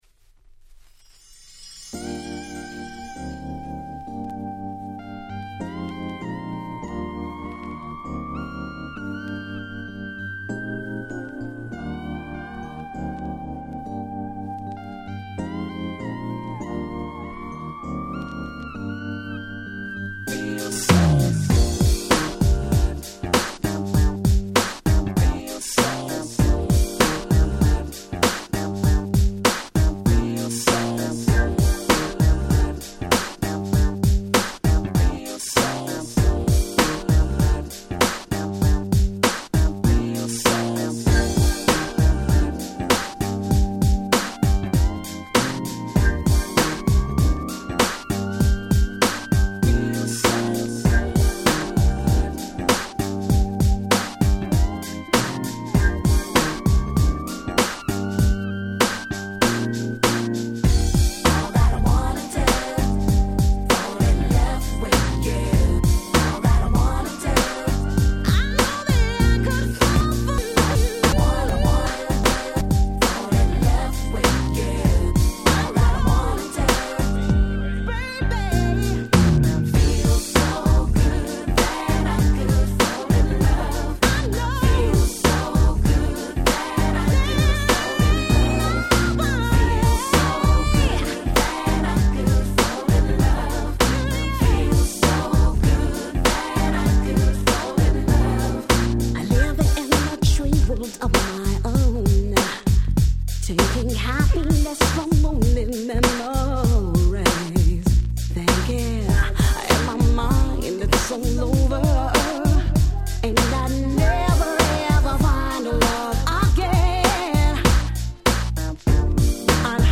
96' Nice UK R&B !!